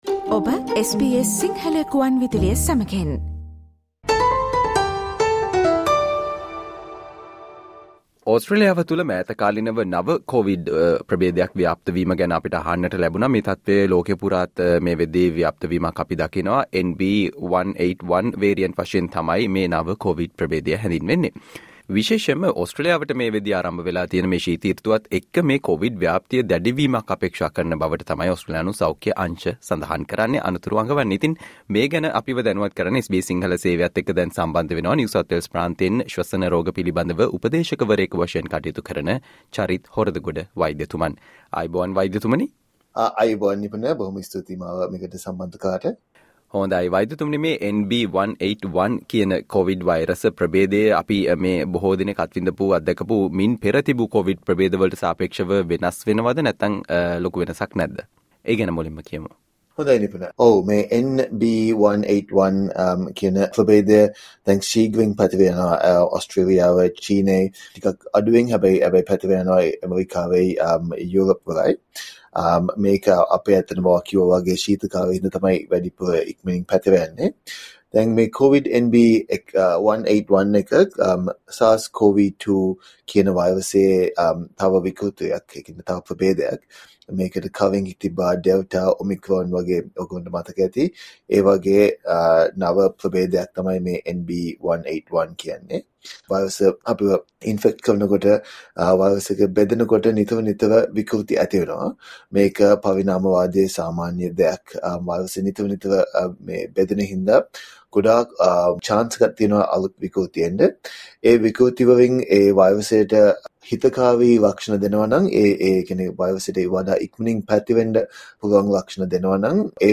ඕස්ට්‍රේලියාවට මේ වනවිට ආරම්භ වී තිබෙන ශිත සෘතුවත් සමග NB.1.8.1 variant වශයෙන් හැඳින්වෙන නව කොව්ඩ් ප්‍රභේධ ව්‍යාප්තිය භයානකද සහ එමගින් ආරක්ෂා වීමට කල යුතු දේ පිළිබඳව SBS සිංහල සේවය සිදු කල සාකච්චාවට සවන්දෙන්න